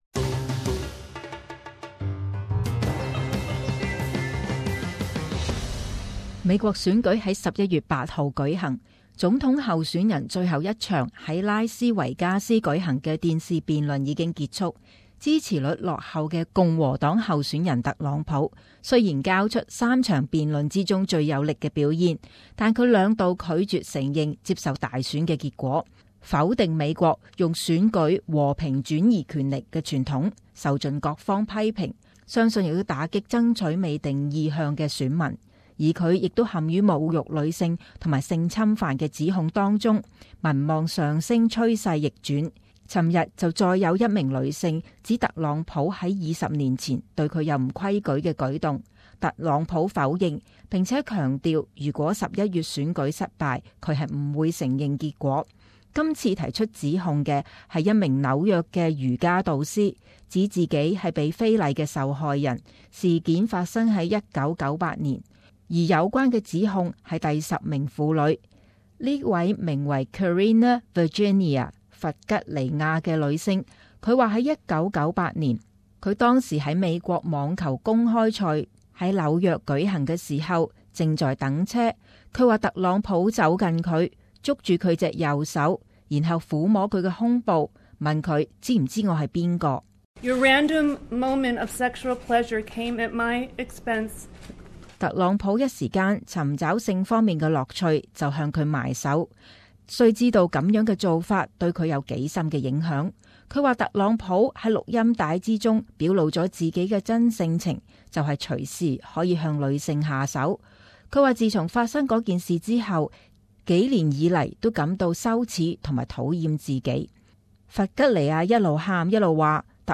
【时事报导】再有妇女声称被特朗普侵犯